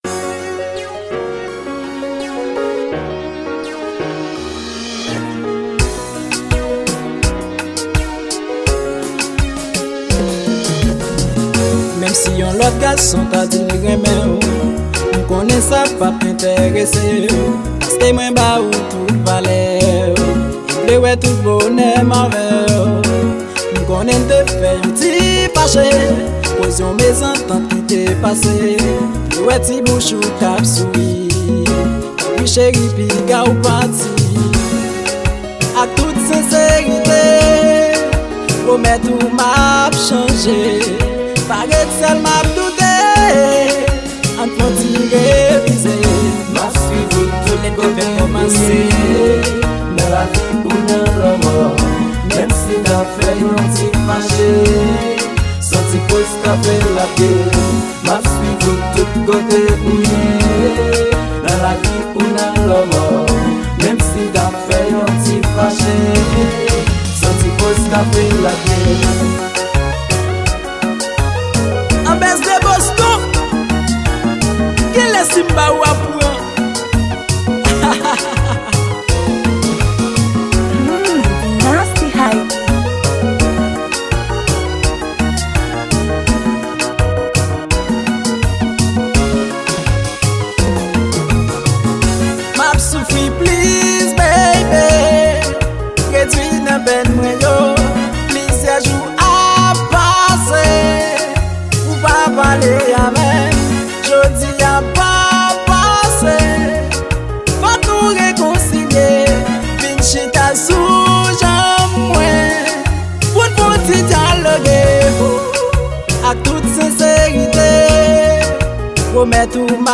Genre: Compas.